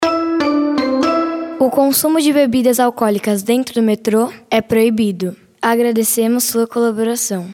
OUÇA: Transportes de São Paulo recebem avisos sonoros gravados por crianças
Já no Metrô, entre 11 e 31 de outubro, os avisos sonoros educativos e de gentileza são feitos nas vozes de nove crianças.